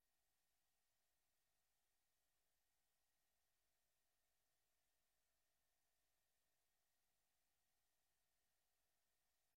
Commissie Fysiek Domein 05 september 2023 19:30:00, Gemeente Stichtse Vecht
Locatie: Koetshuis, Markt 13 3621 AB Breukelen